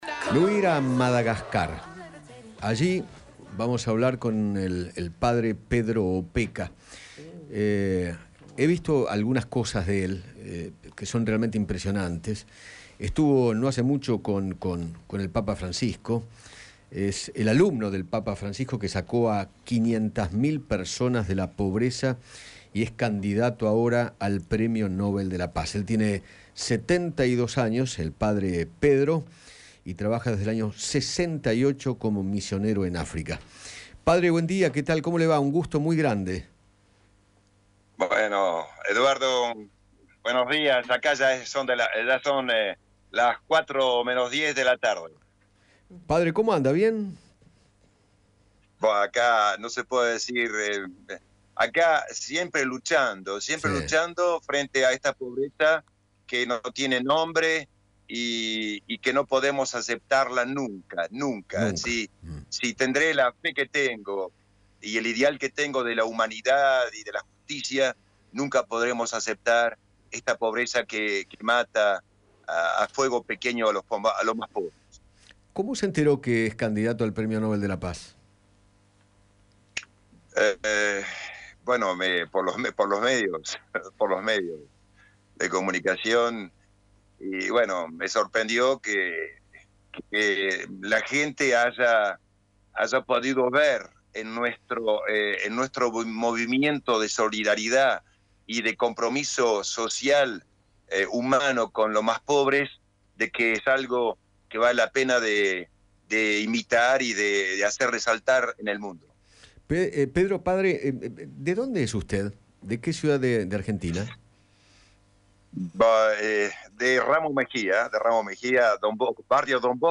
Eduardo Feinmann dialogó con Pedro Opeka, quien se encuentra en Madagascar, donde misiona como miembro de una Congregación de Padres Vicenticos y está nominado al Premio Nobel de la Paz.